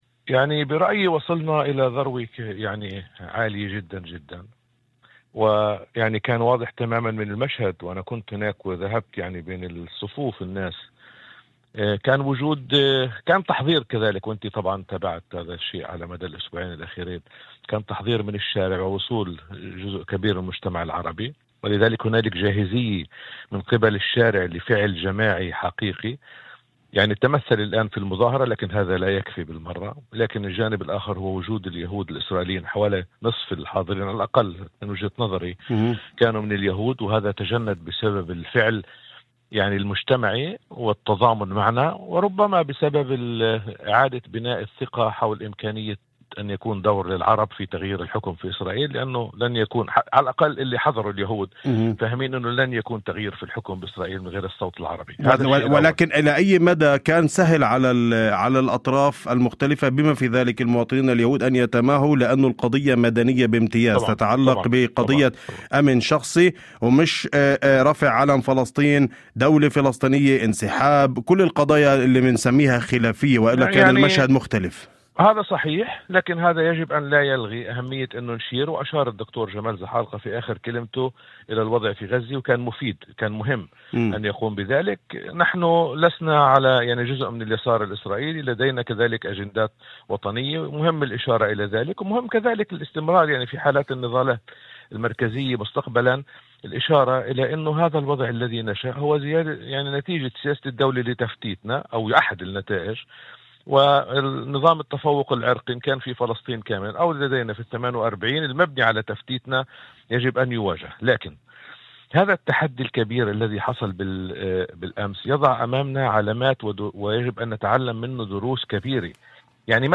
وأضاف في مداخلة هاتفية لبرنامج "أول خبر"، على إذاعة الشمس، أن اللافت في المشهد كان المشاركة الواسعة لليهود الإسرائيليين، موضحا أن "نصف الحاضرين على الأقل كانوا من اليهود، وهذا يعكس تضامنا حقيقيا، إضافة إلى إعادة بناء الثقة بإمكانية أن يكون للعرب دور حاسم في تغيير الحكومة في إسرائيل".